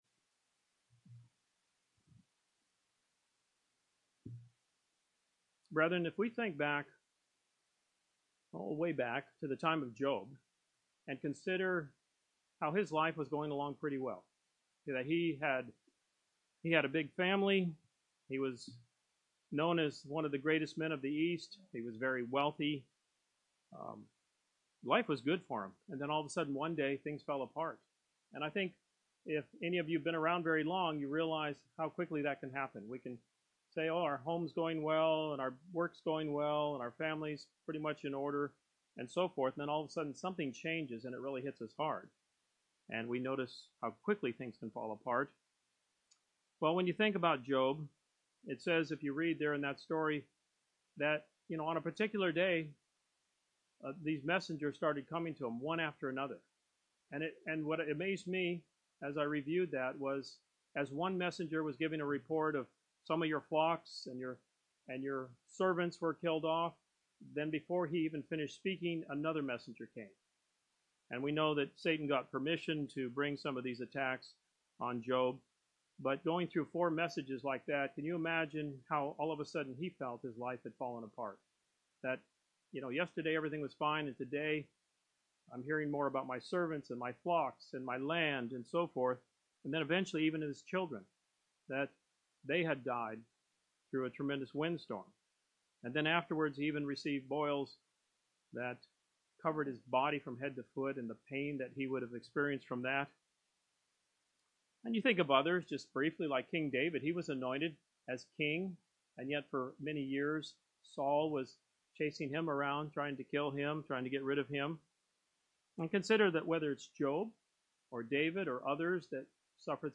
This sermon offers four ways in which the scriptures remind us to grow in hope.
Given in Tampa, FL